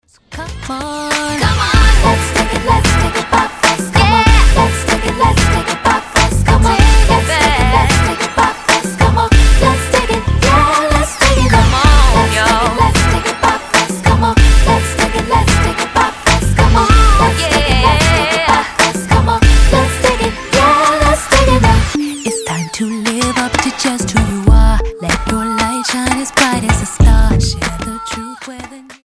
Girl trio
Style: R&B